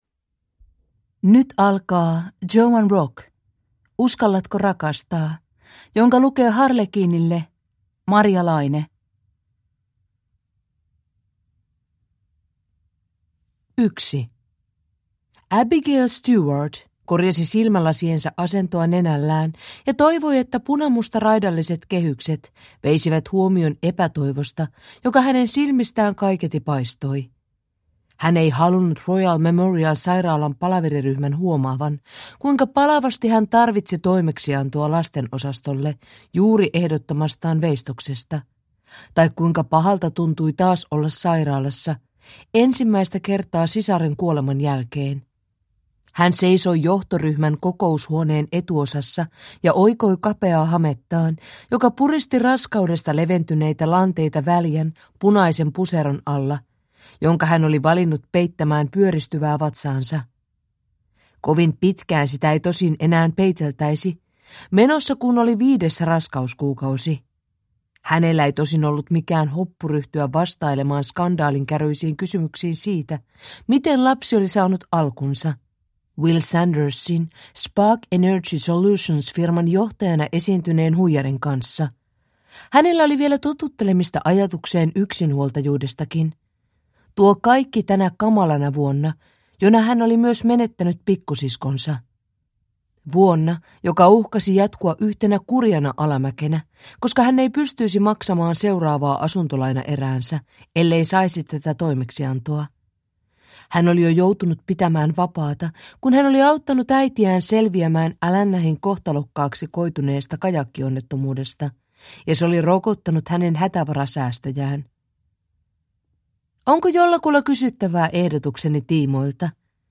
Uskallatko rakastaa? – Ljudbok – Laddas ner